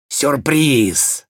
Звук шпиона из Team Fortress 2 с фразой Непредсказуемость